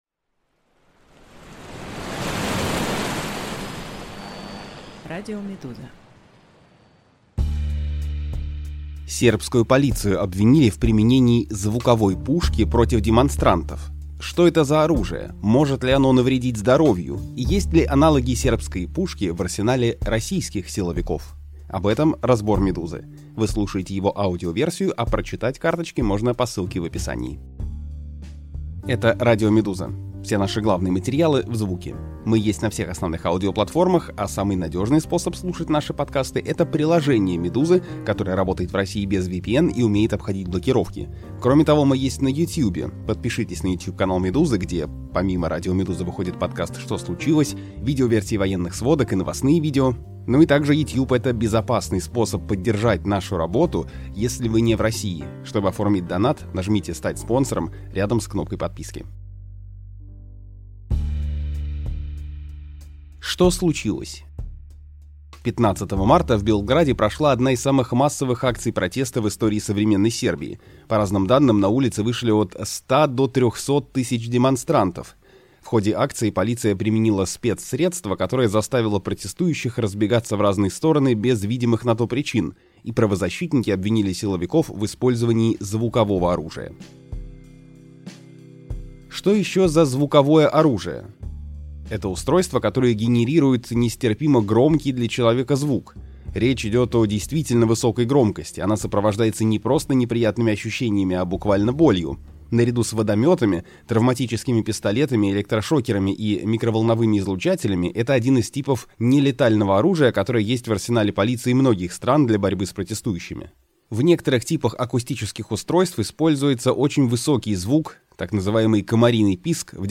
Аудиоверсии главных текстов «Медузы».